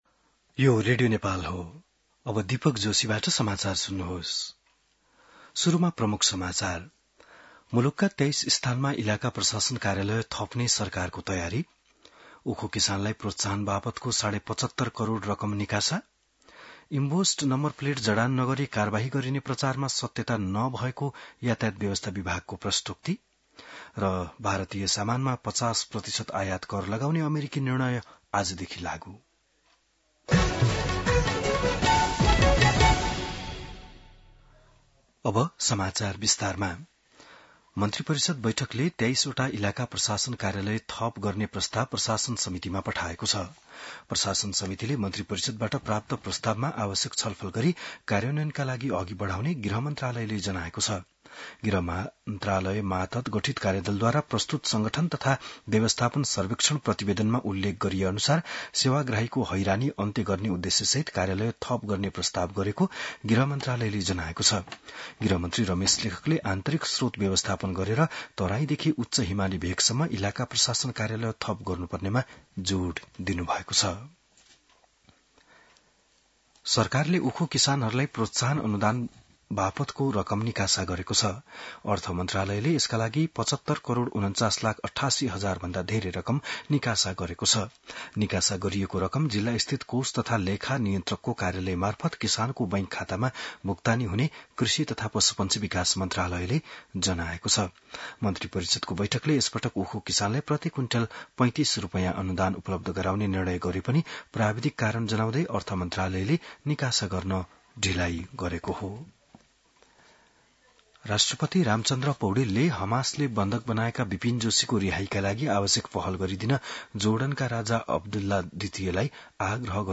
बिहान ९ बजेको नेपाली समाचार : ११ भदौ , २०८२